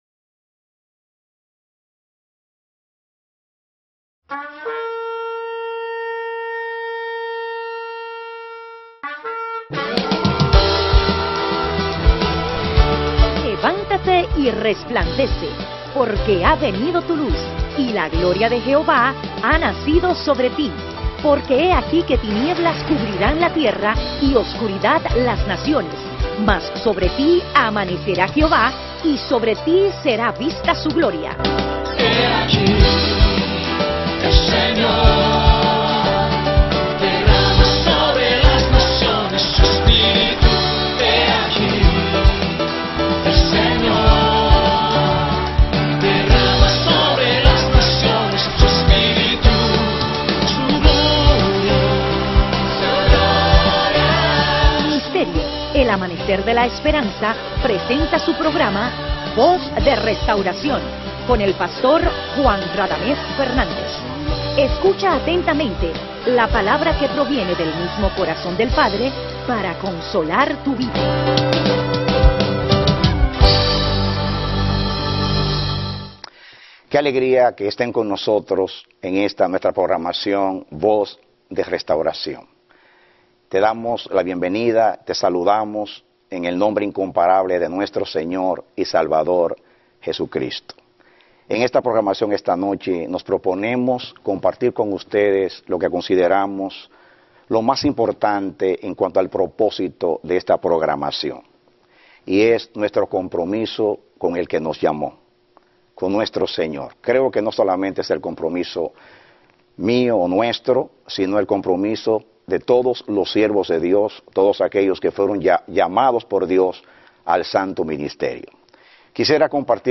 A mensajes from the series "Conociendo a Dios."